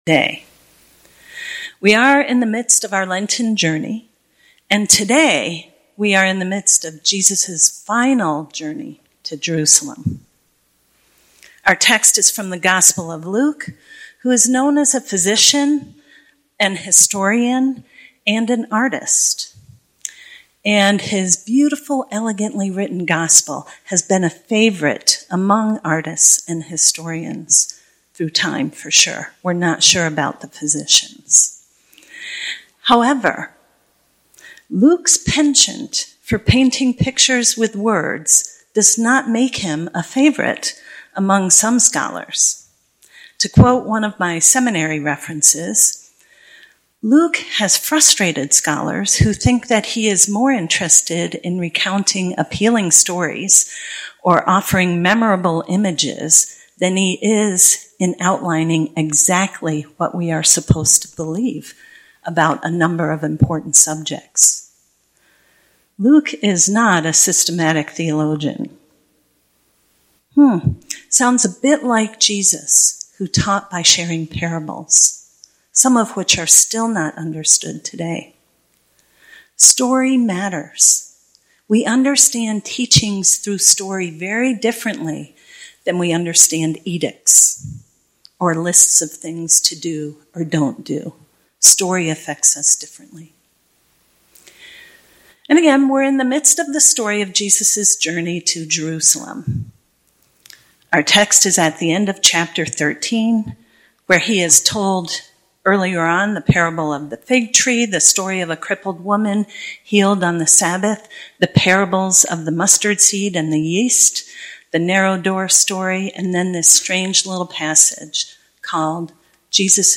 Sermon Podcast for First Baptist Church of Edmonton, Alberta, Canada